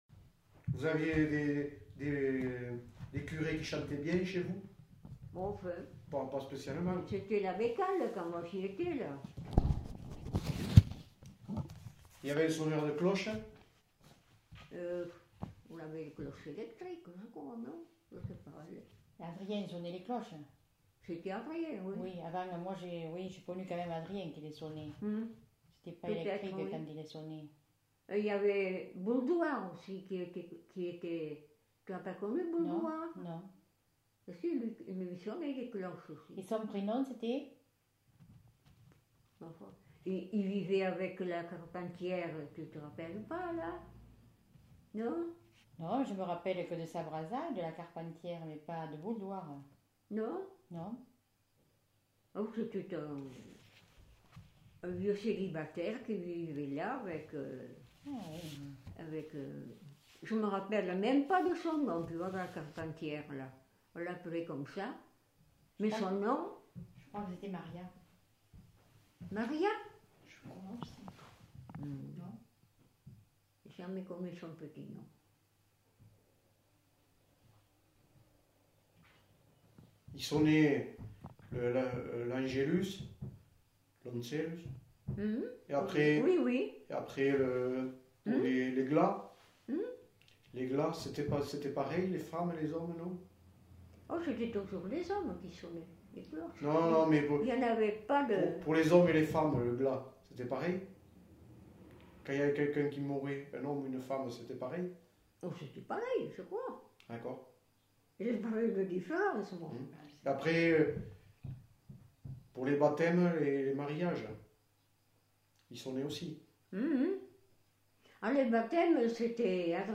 Lieu : Gramat
Genre : témoignage thématique